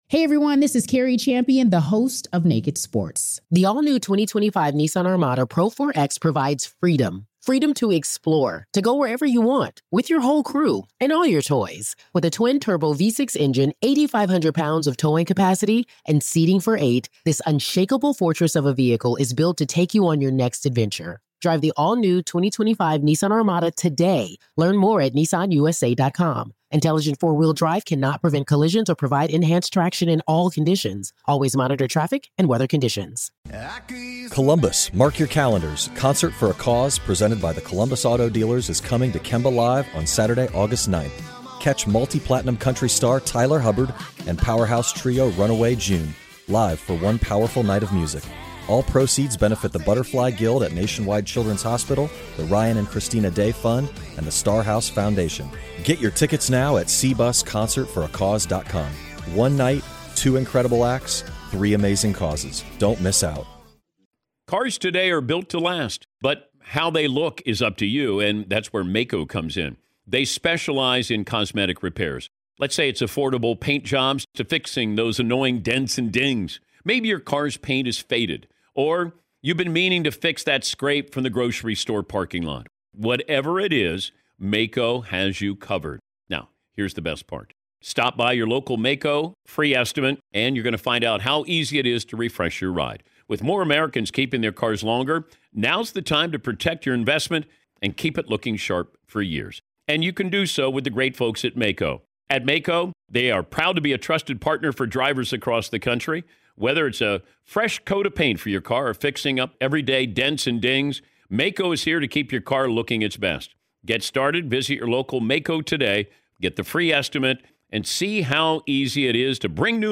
Welcome to a new episode of the Hidden Killers Podcast, where we bring you live courtroom coverage of some of the most gripping and heart-wrenching cases.